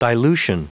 Prononciation du mot dilution en anglais (fichier audio)
dilution.wav